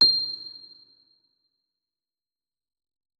electric_piano